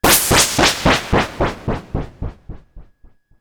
Machine03.wav